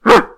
动物、野兽和怪兽 " 吠声
描述：大狗吠一声。 （没有动物受到伤害 这个声音是由一个人类女性完成的。）
标签： 怪物 树皮 动物 树皮 大狗 吠叫 深树皮
声道立体声